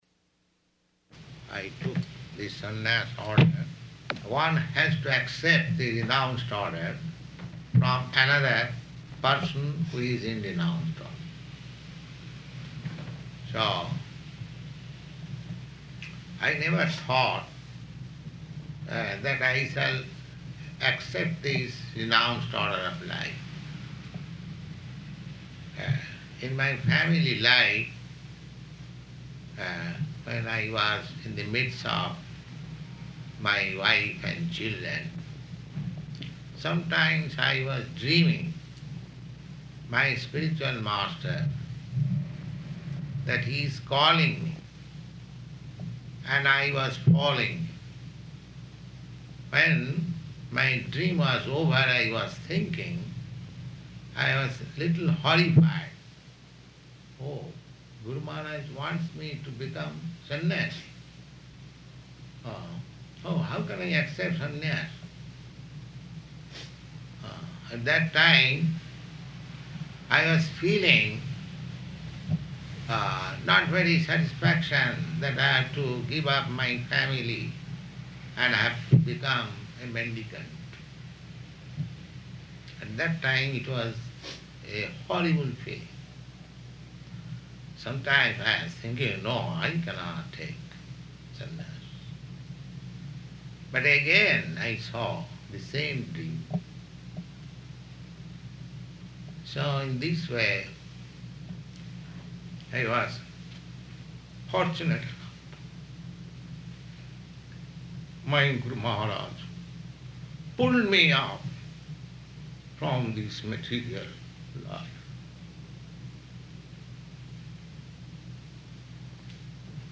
Bhaktiprajñāna Keśava Mahārāja's Disappearance Day Lecture,
Location: Seattle